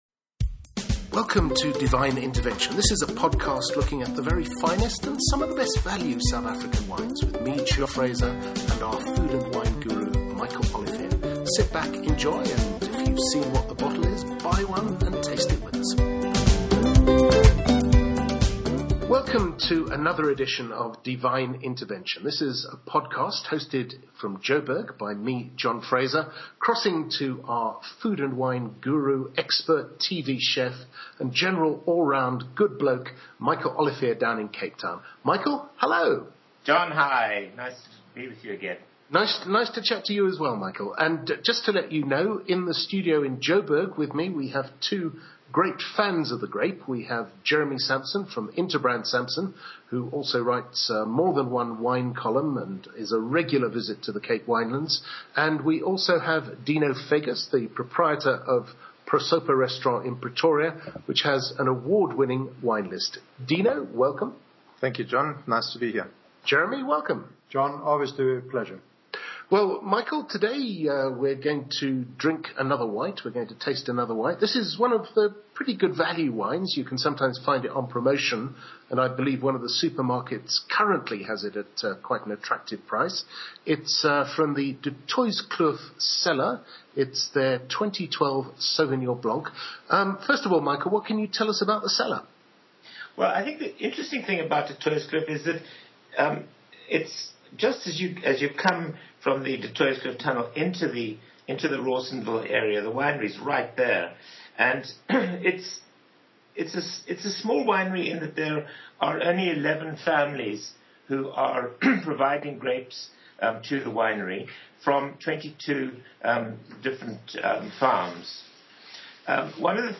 Studio guest tasters